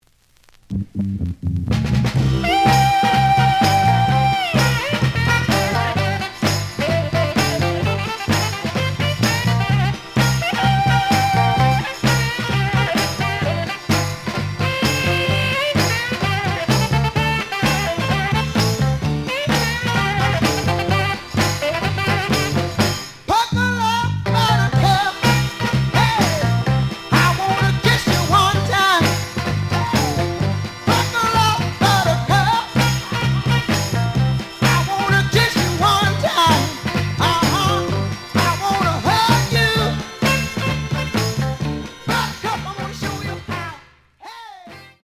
The audio sample is recorded from the actual item.